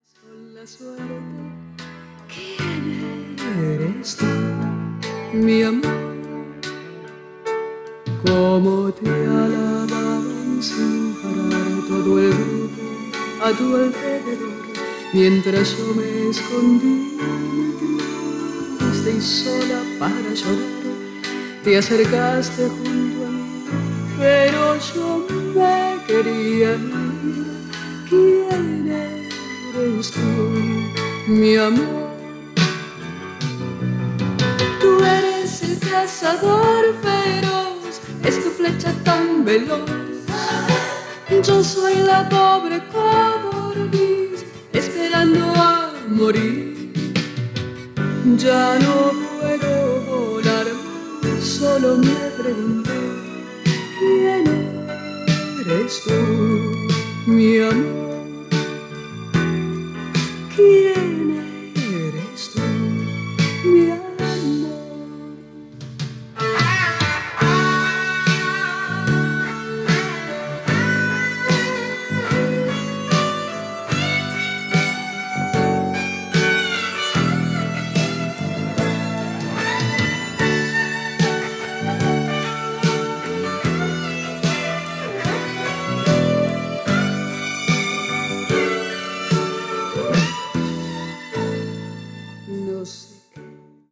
アルゼンチン・シンガー